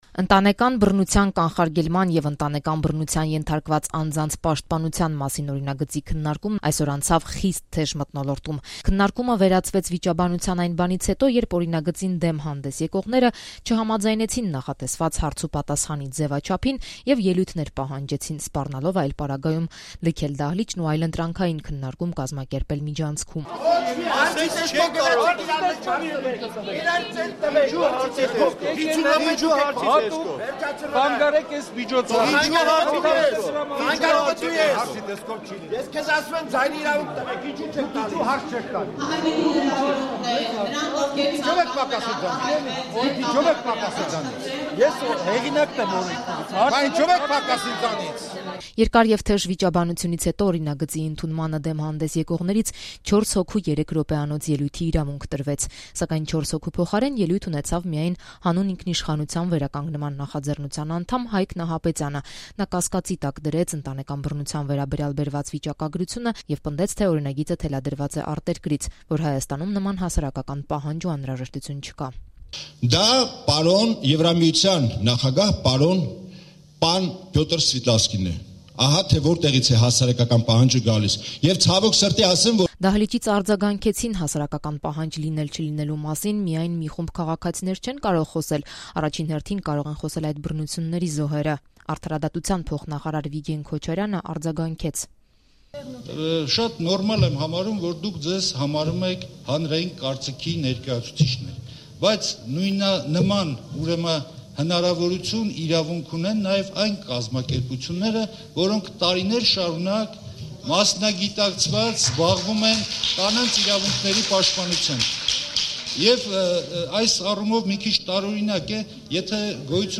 Ընտանեկան բռնությանը վերաբերող օրինագծի հանրային քննարկումն անցավ խիստ թեժ մթնոլորտում
Ռեպորտաժներ